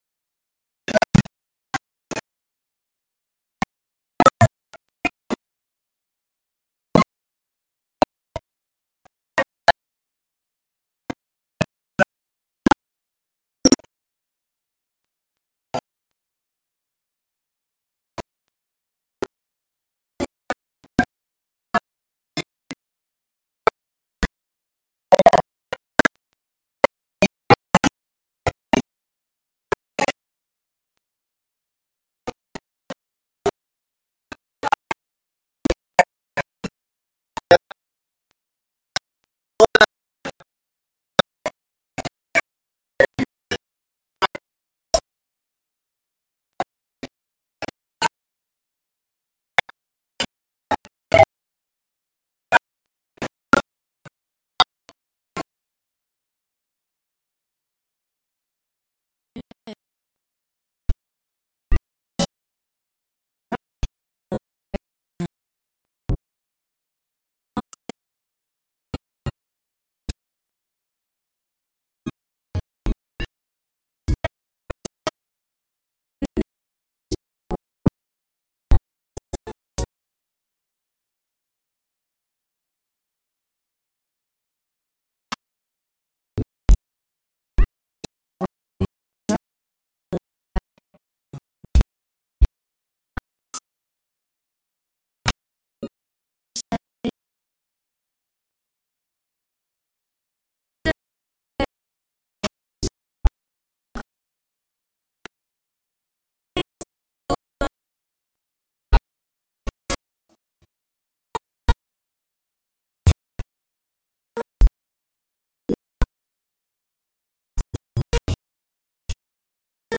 Live from the Catskill Clubhouse.
LIVE - Youth Clubhouse TRAX Performance Play In New Tab (audio/mpeg) Download (audio/mpeg)